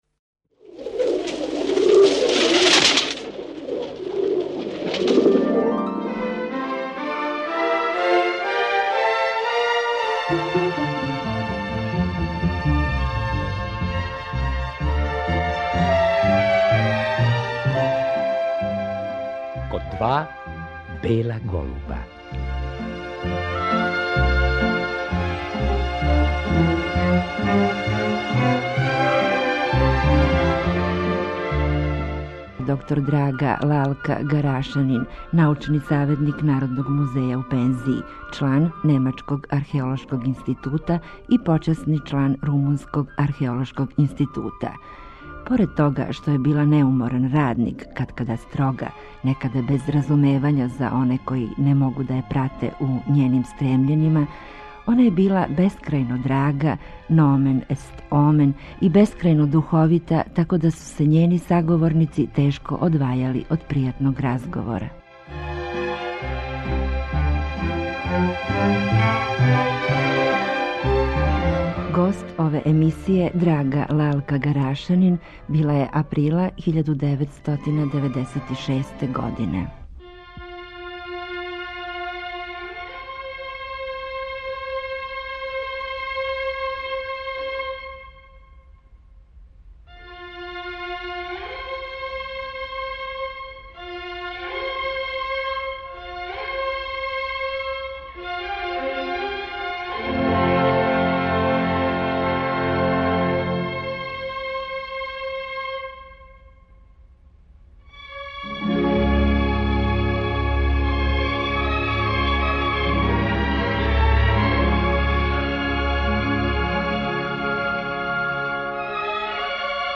Реприза